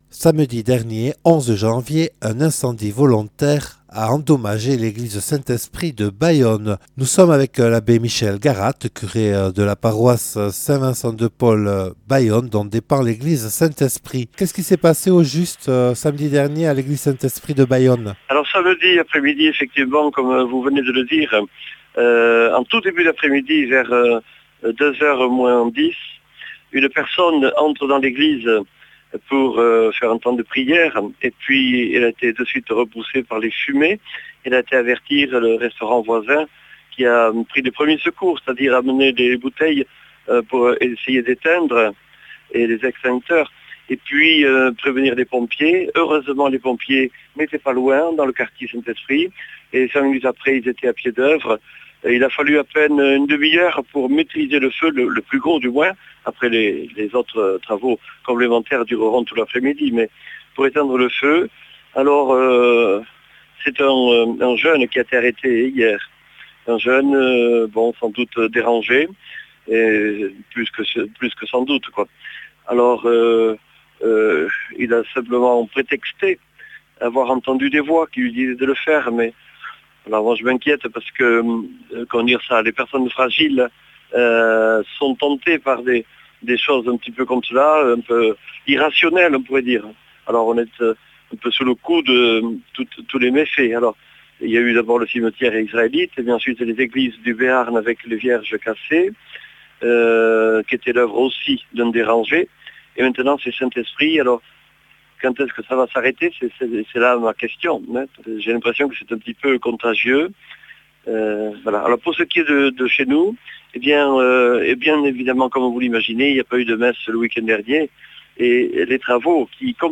Accueil \ Emissions \ Infos \ Interviews et reportages \ Incendie à l’église Saint-Esprit de Bayonne – ; statues brisées de la Vierge (...)